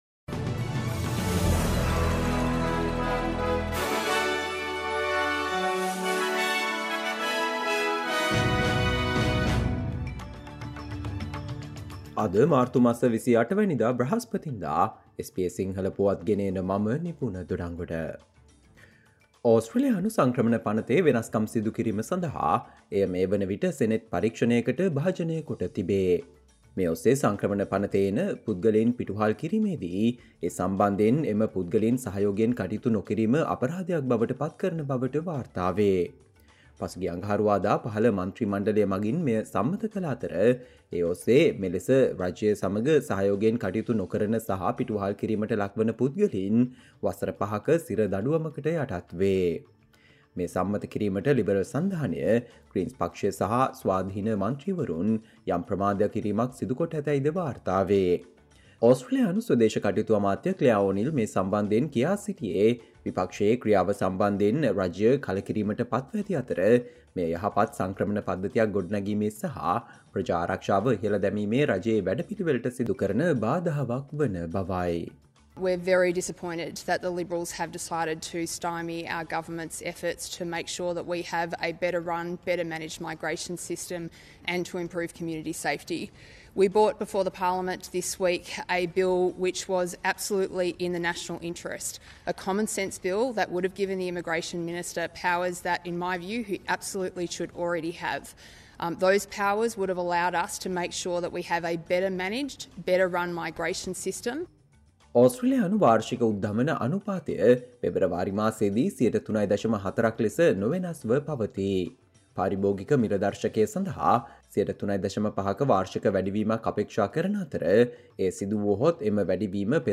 Australia news in Sinhala, foreign and sports news in brief - listen, Thursday 28 March 2024 SBS Sinhala Radio News Flash